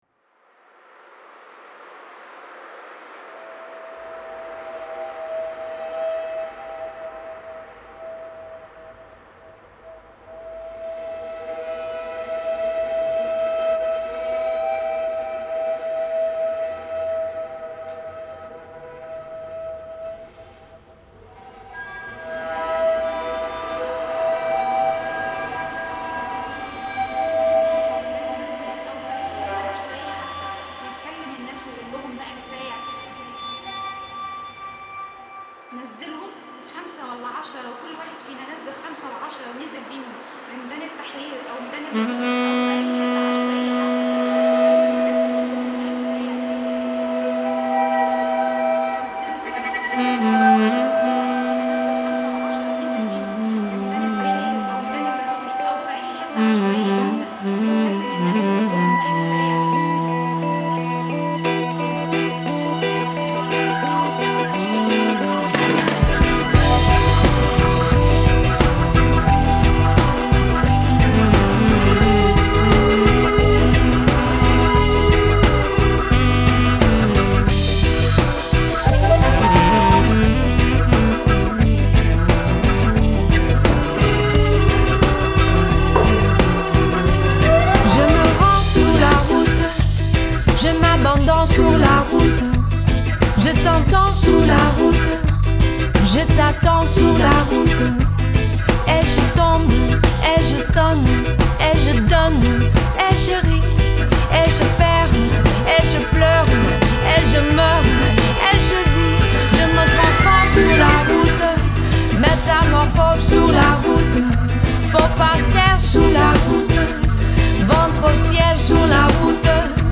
Genre: Folk-jazz, chanson, world, reggae